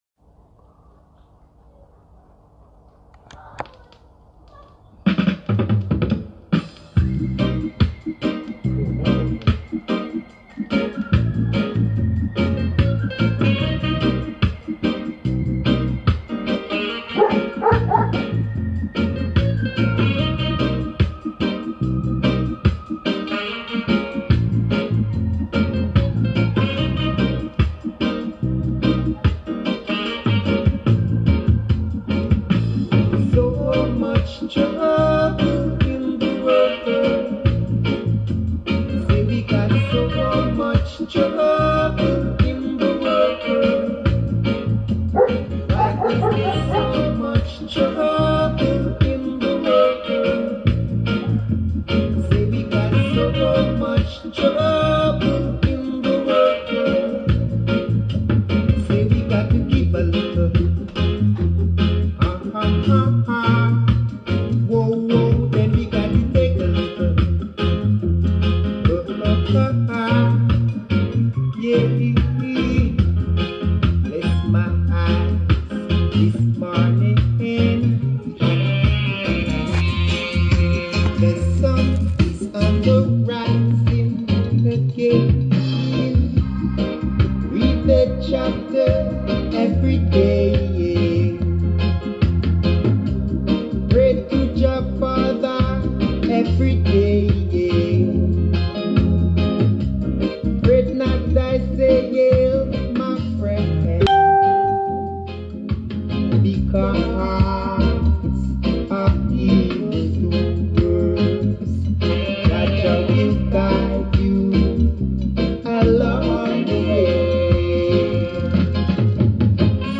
There were some gaps which I edited together.